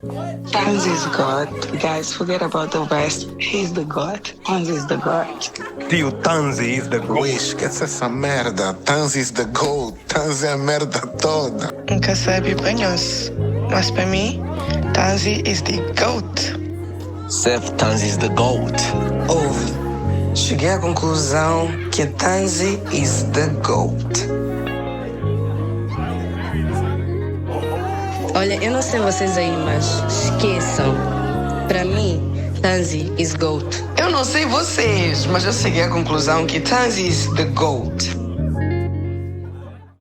Kizomba 2024